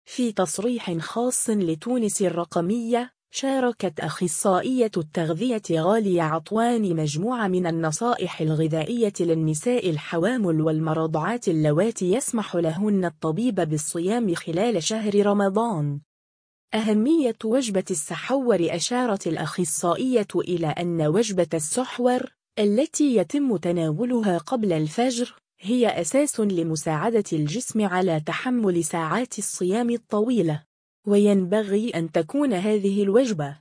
في تصريح خاص لــتونس الرقمية